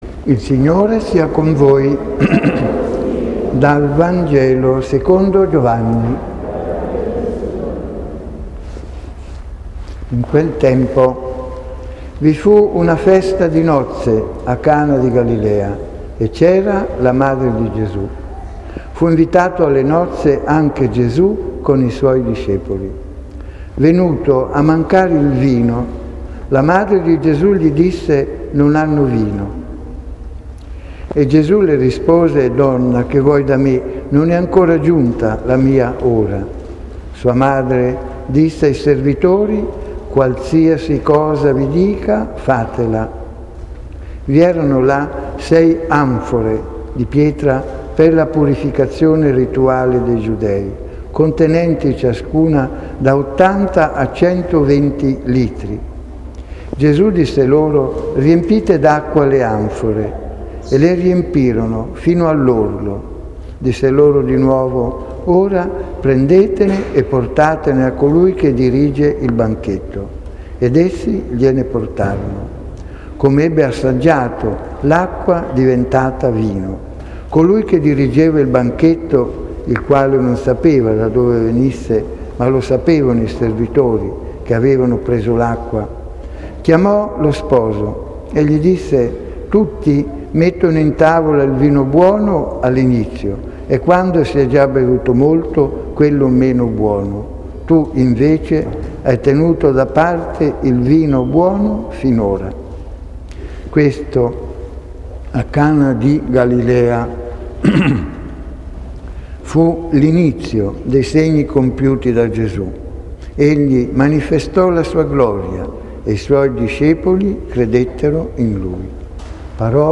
◄ Giotto - Wikipedia - Le Nozze di Cana: il Matrimonio, la Famiglia - La Madonna... padrona di casa - Giornata dei migranti e dei rifugiati - S. Antonio (ed il Pane per tutti). Clicca sul link con il simbolo mp3 per ascoltare l'omelia OM_S.Antonio [22.255 Kb] B A T T E S I M O del Signore e il NOSTRO Battesimo Clicca sul link con il simbolo mp3 per ascoltare l'omelia OM160110 [14.041 Kb] Vogliamo "Mamma e Papà" e non il Cirinnà!